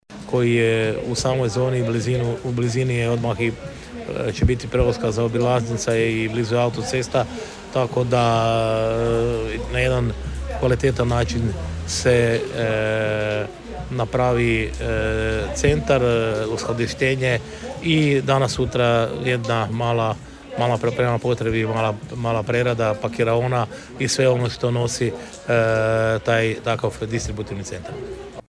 Gradonačelnik Ljubomir Kolarek: